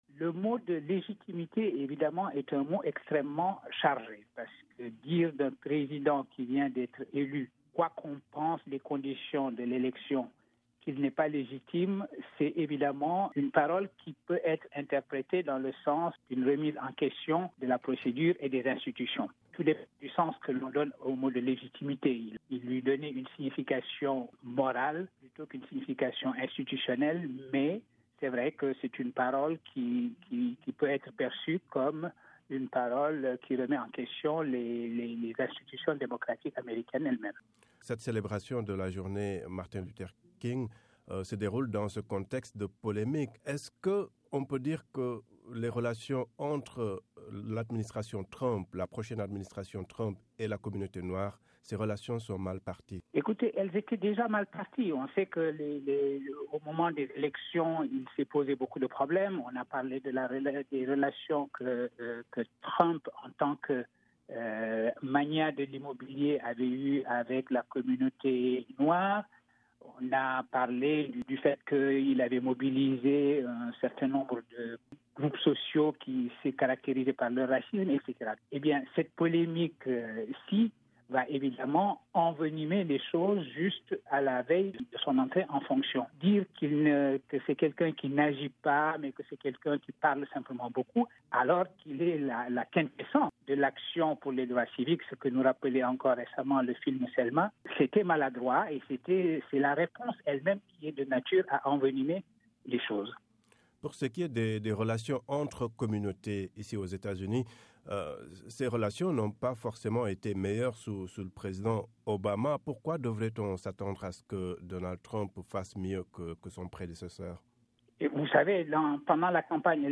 Brèves Sonores
La journée Martin Luther King célébrée lundi aux Etats-Unis dans un contexte de polémique entre le président élu et un ancien compagnon du pasteur noir assassiné en 1968. L'analyse de Souleymane Bachir Diagne, professeur à Columbia university